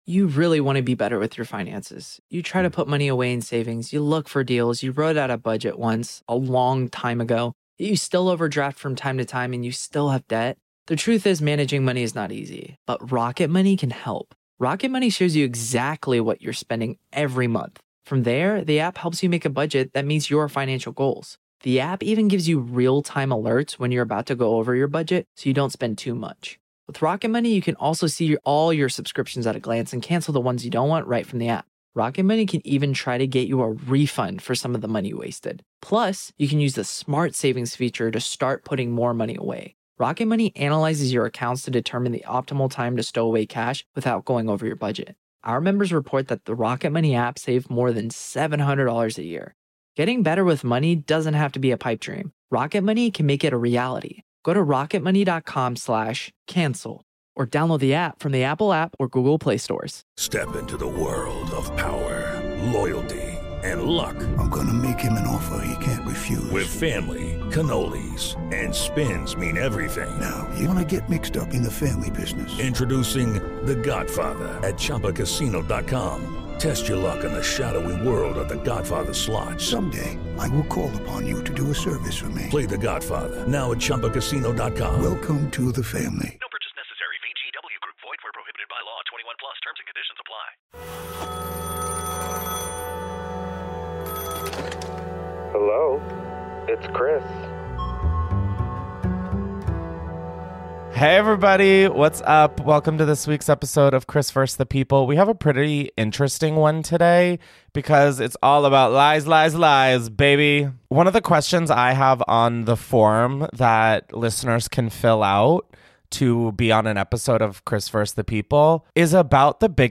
Every story this week centers on deception…and somehow, each one manages to outdo the last. First, a caller shares how her ex not only cheated, got married, and had a baby immediately after their breakup, but then catfished her for months for reasons of his own. Then, another listener reveals that she grew up believing a man was her dad... until middle school genetics homework exposed that literally her entire family had been lying to her.